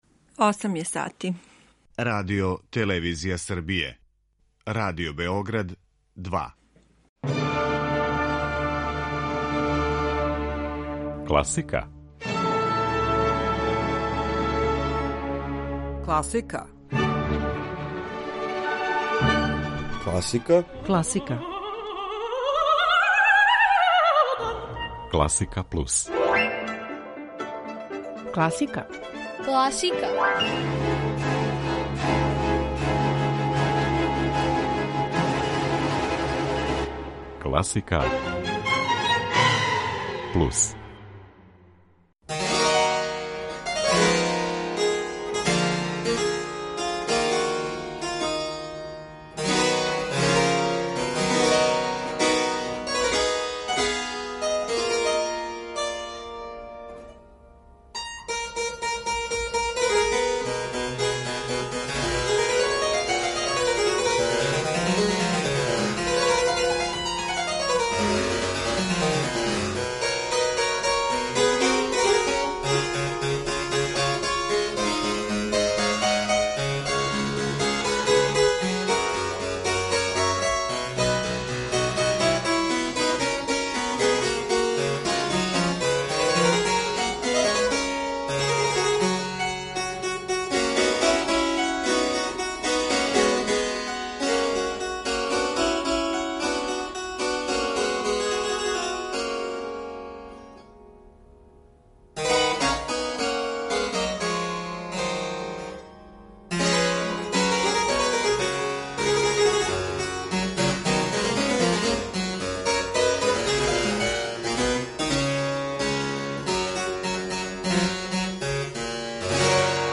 Блештави симфонијски оркестар Оторина Респигија за енергични почетак дана, музика Јохана Себастијана Баха за „прочишћење душе", звук оригиналних инструмената у квартету Волфганга Амадеуса Моцарта, као и дела савремене српске композиторке Иване Огњановић и мало познатог руског аутора Сергеја Борткијевича чине разноврстан избор ове наше уметнице, који истовремено сведочи и о ширини њених интересовања као извођача.